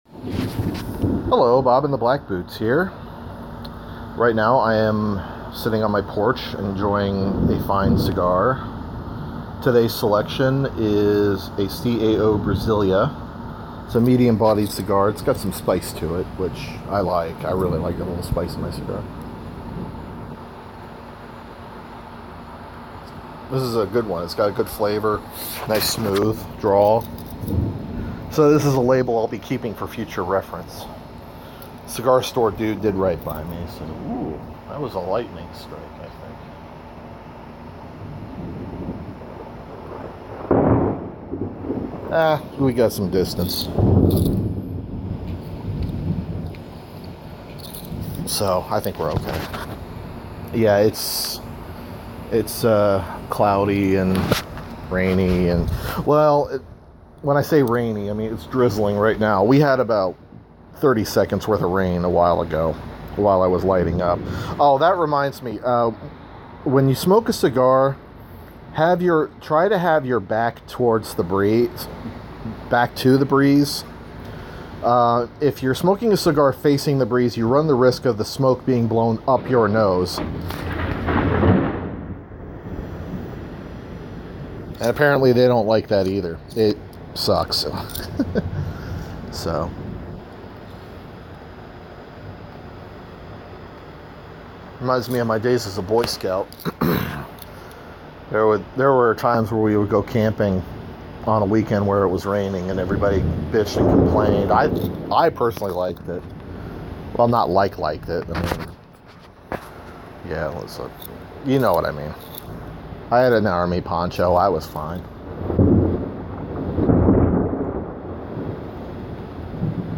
Smoking a cigar on the porch when the storm comes.